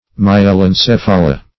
Search Result for " myelencephala" : The Collaborative International Dictionary of English v.0.48: Myelencephala \My`e*len*ceph"a*la\, n. pl.